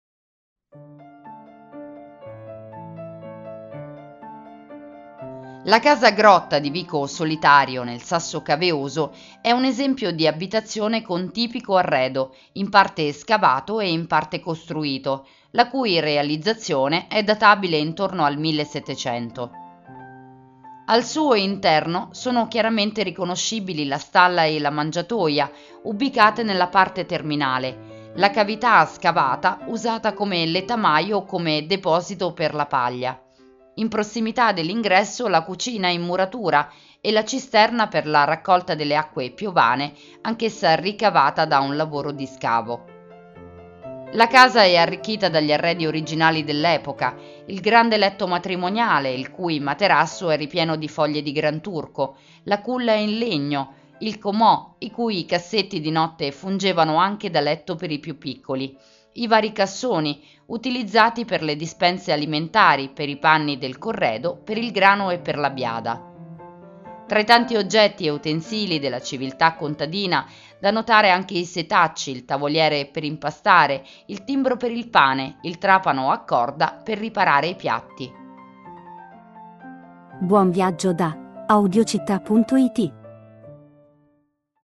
Audioguida Matera – Casa Grotta di Vico Solitario